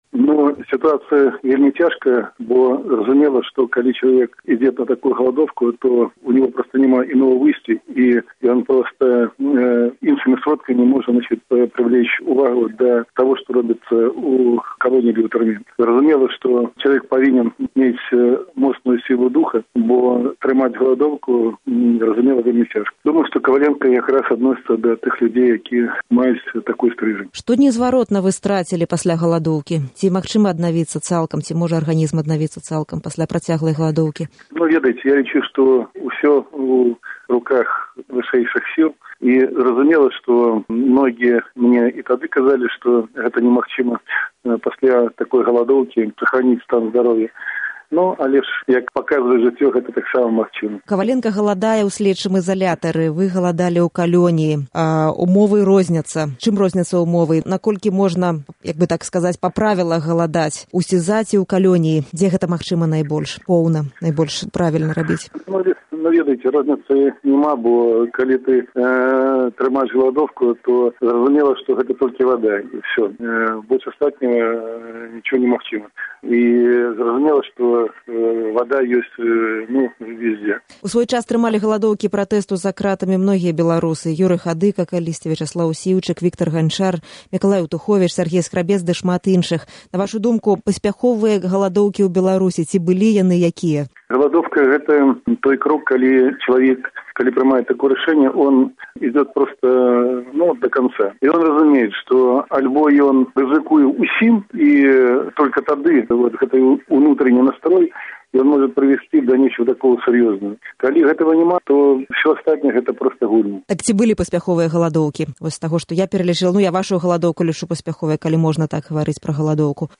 Гутарка з Аляксандрам Казуліным, 18 студзеня 2012 году